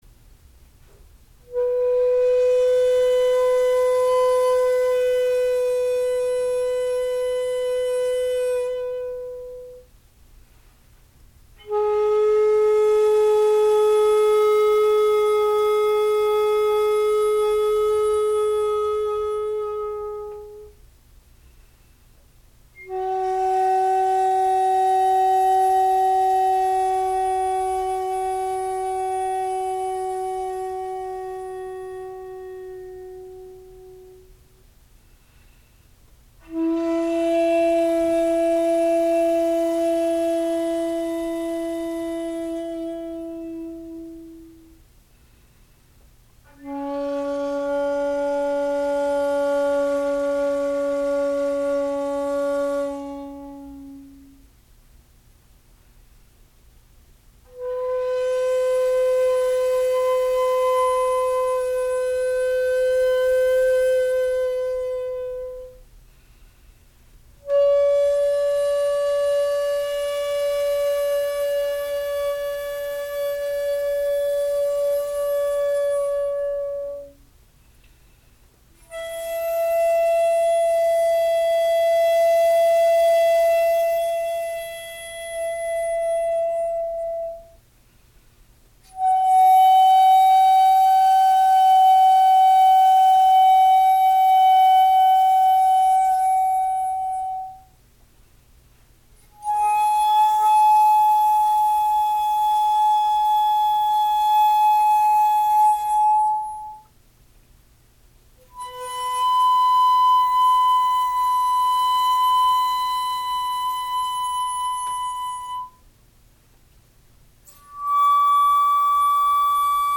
ピッチピッチ（音程）と喧しく叫ばれる前の時代ですので現代管よりは音が低めですが、その分、落ち着いた響きです。
録音はしていますが、所詮機械ですので生の音にはほど遠いことをご留意ください。
a.全音の各音－＞素直な音色です。